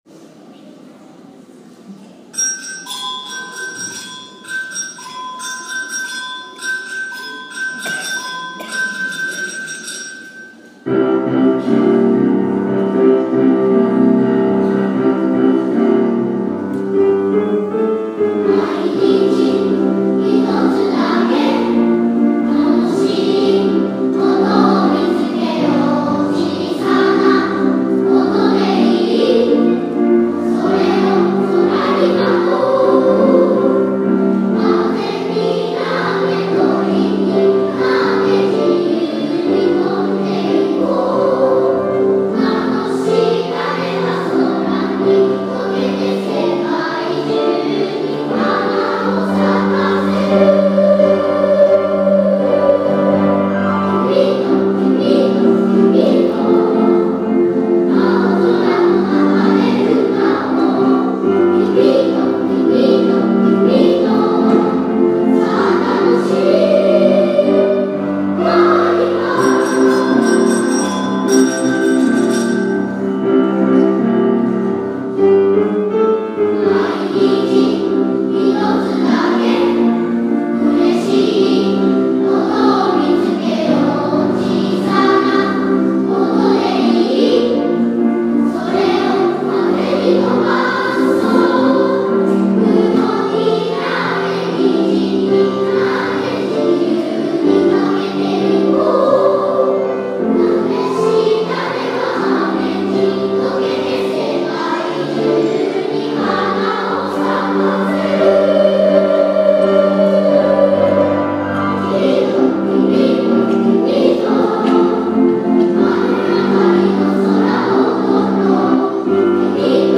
2016年6月4日（土）ふれあい科　１１ｔｈ大空創立記念コンサート
大空のカーニバル」２部合唱です。
サンバのリズムでうきうき楽しくなる曲です♪３年生も４年生もアルトとソプラノの中から自分が選んだパートにチャレンジしました。